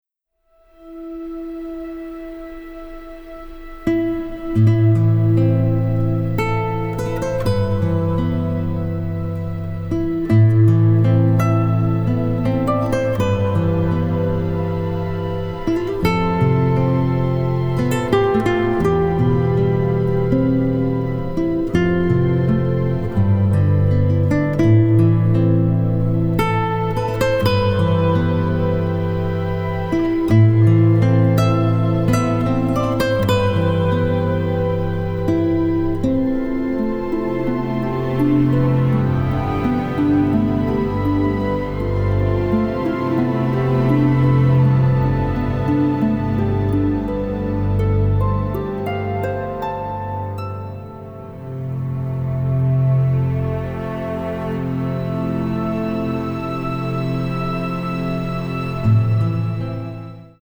a very emotional and sensitive score
especially cello, harp and guitar.